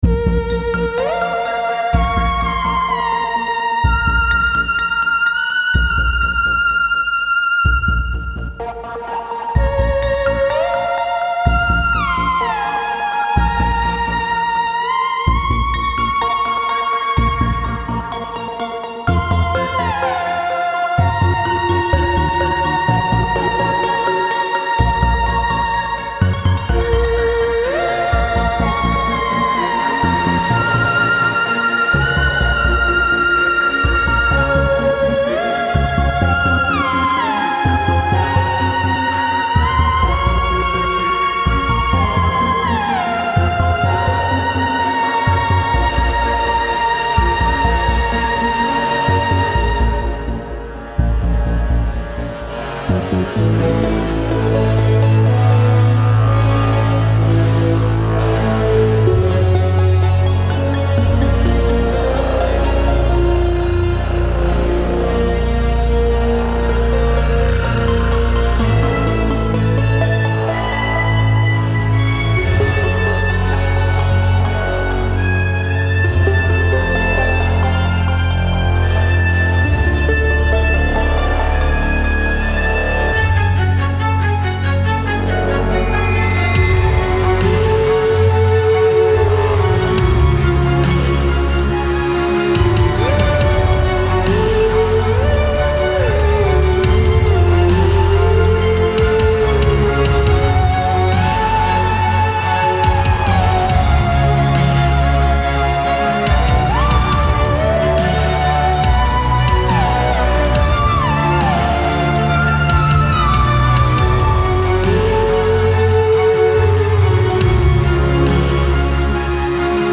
Time Travel - Orchestral and Large Ensemble - Young Composers Music Forum